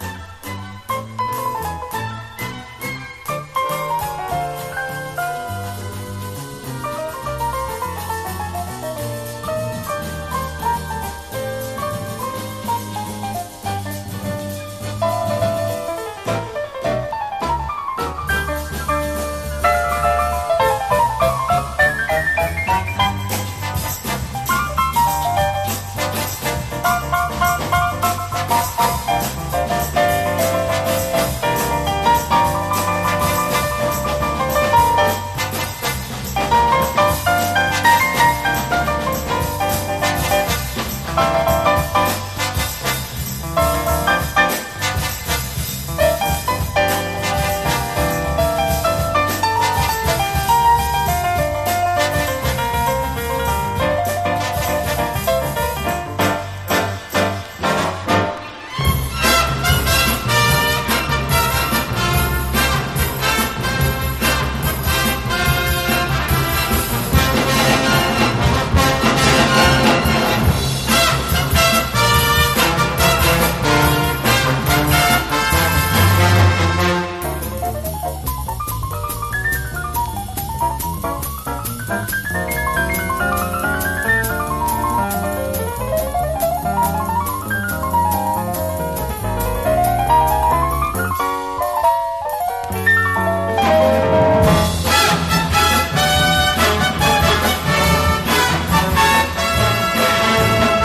華やかな美麗オーケストラル・ピアノ・ラウンジ大傑作！
ジャズ、クラシック、ロック、カントリー、ブルースなどを横断し